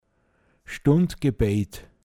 pinzgauer mundart
Gebetsstunden in der Karwoche (ortschaftsweise durchgeführt) Stundgebäit, n.